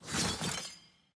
sfx-regalia-lobby-buildin-bronze.ogg